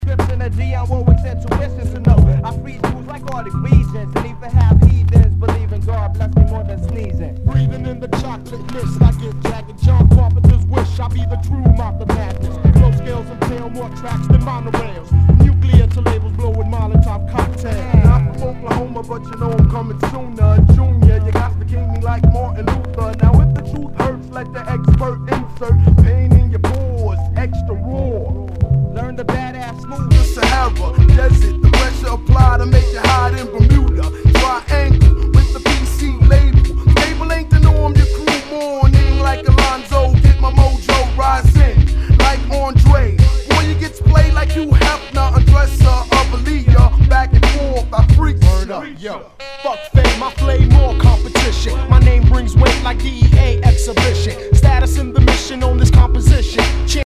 HIPHOP/R&B
全体にチリノイズが入ります
[VG-] 傷や擦れが目立ち、大きめなノイズが出る箇所有り。